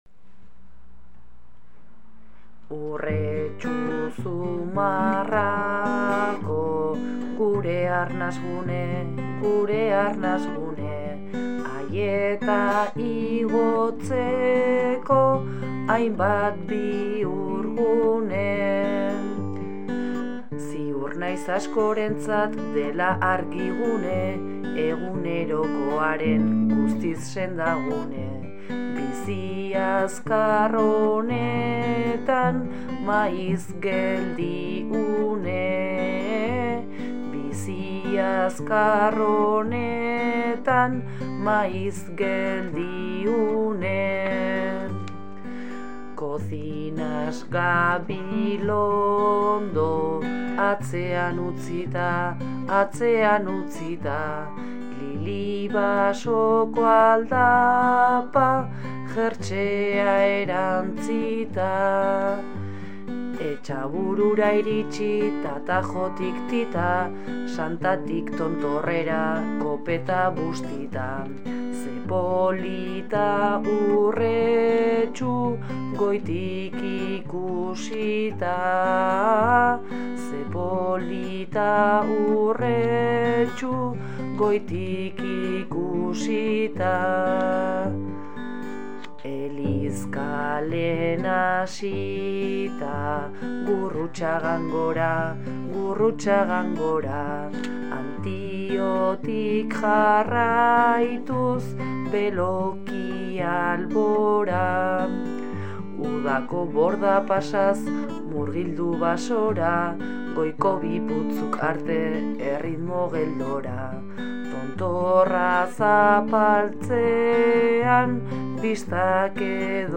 konexio' bertso sorta.